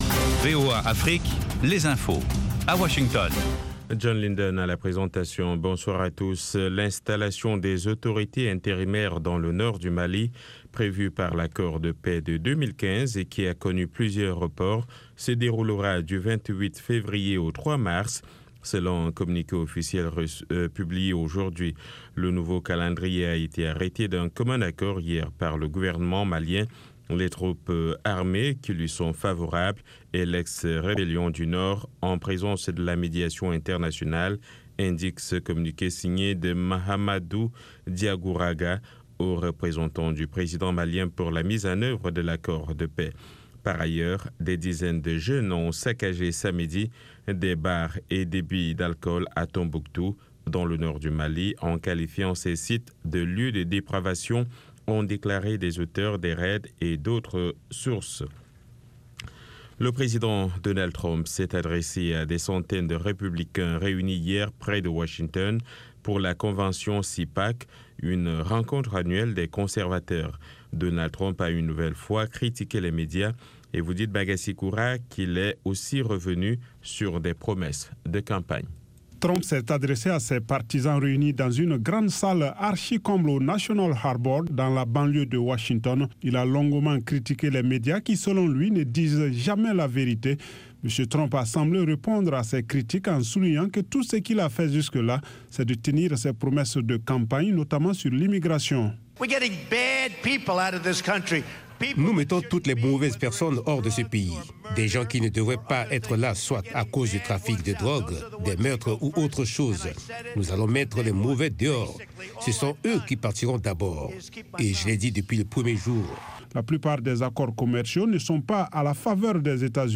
- Les orchestres gigantesques et les groups qui ont grandement contribué a changer les mœurs et société, ce classement comprend divers genre musicaux (Rap, Rock, Pop, R&b etc.) afin de satisfaire le plus grand nombre.